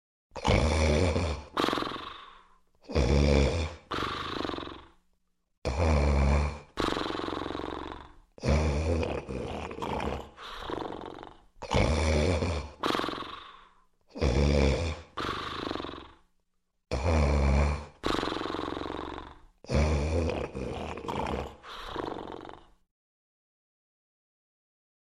loud-snoring_24840.mp3